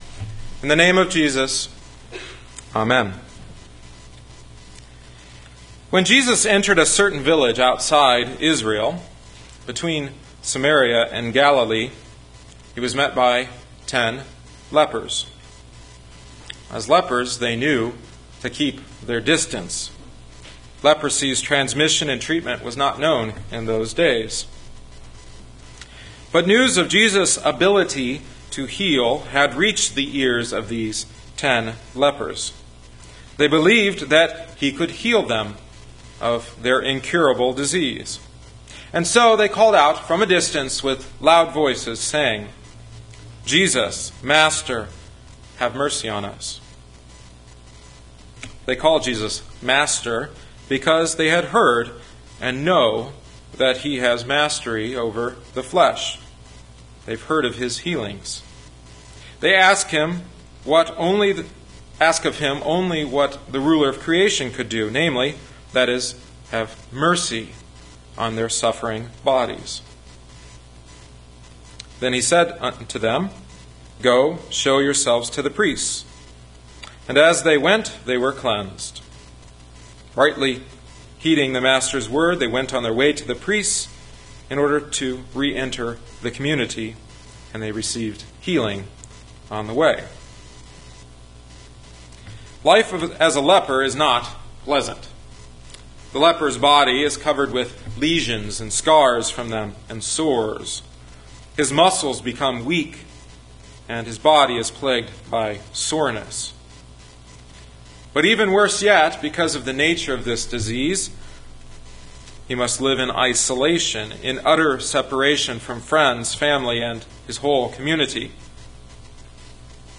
Grace Lutheran Church – Dyer, Indiana